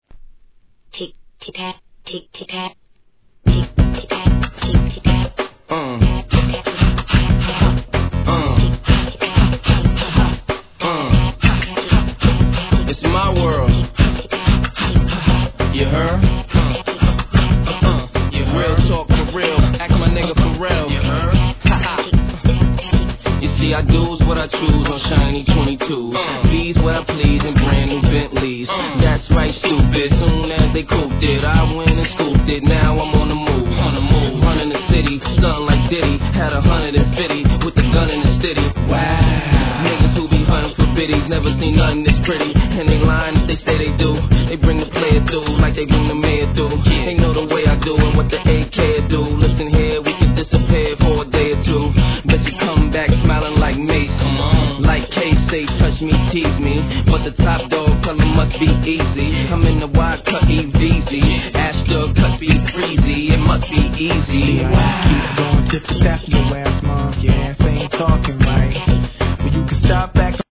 HIP HOP/R&B
「ティット、タット♪」の上音+フックの「ドォロロロロント♪」がかなり中毒性有り!!